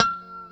B4 PICKHRM1D.wav